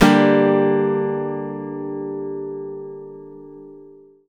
OVATION G-MO.wav